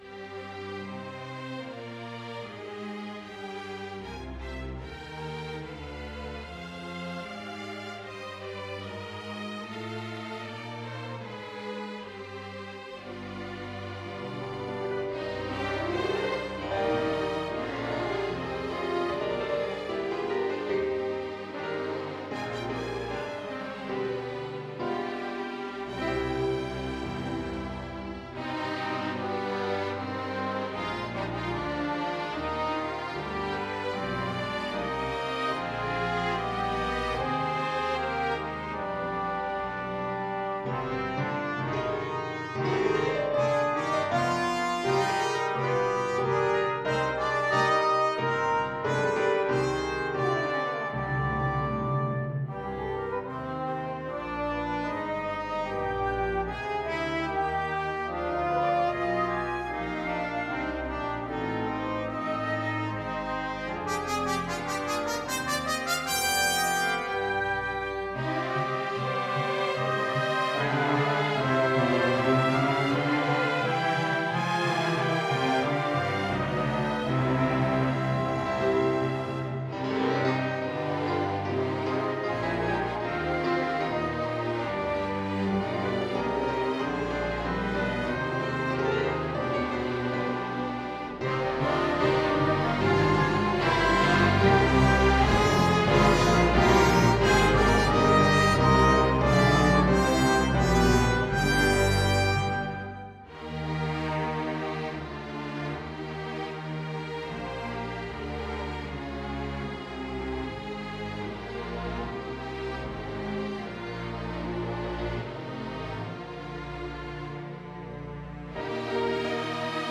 Suite symphonique « Climats »